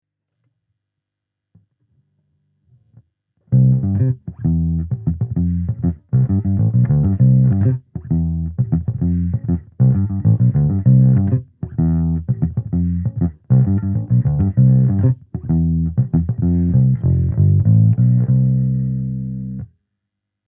ベースアンプ 楽器レンタル 東京 SWR BASIC BLACK
ロックテイストの強いバンドの方にはお薦めするアンプです。